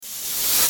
artifact-force-activated1.ogg